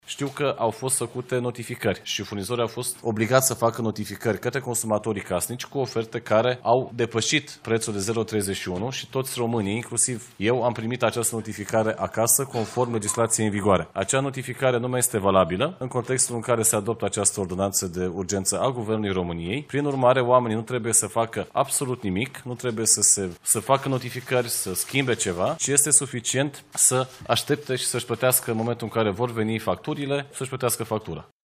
Oamenii nu trebuie să facă absolut nimic, trebuie doar să aștepte să vină factura, a declarat ministrul Energiei, Bogdan Ivan, după ședința de guvern.